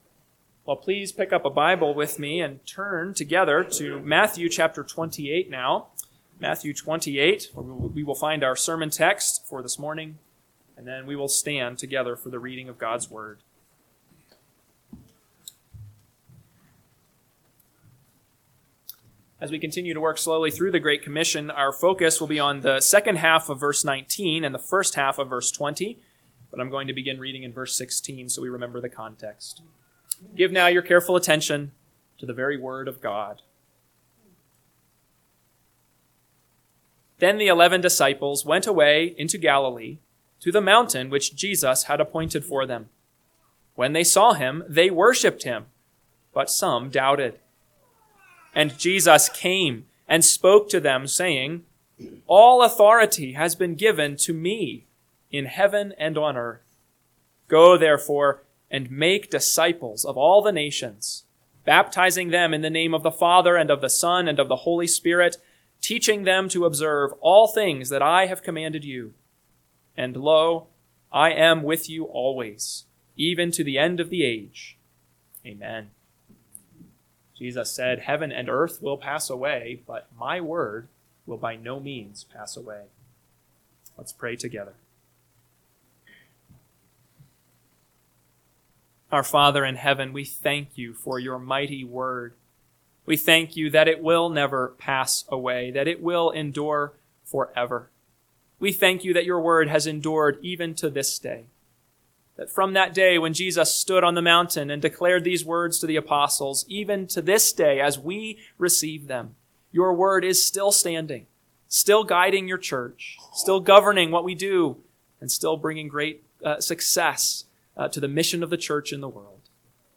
AM Sermon – 6/15/2025 – Matthew 28:19b-20a – Northwoods Sermons